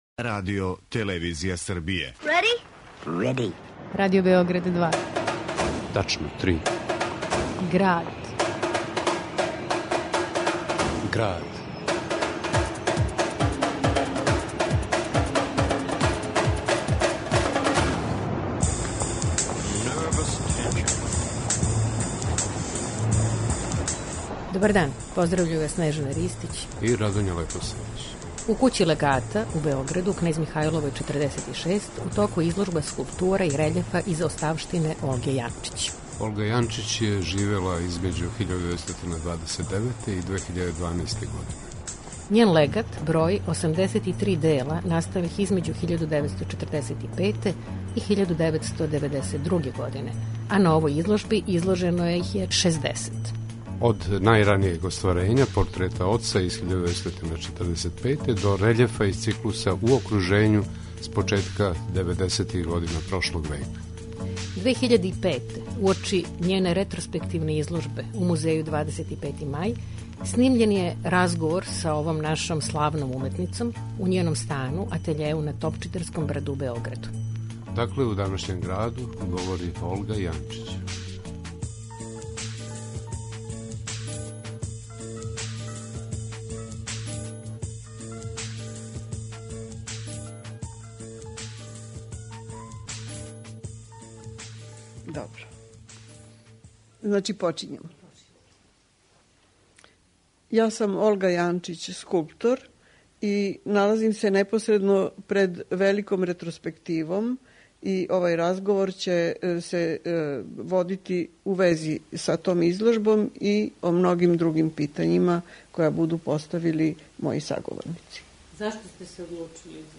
Уочи те друге ретроспективе, 2005, посетили смо је у њеној кући-атељеу на Топчидерском брду у Београду и снимили разговор с њом...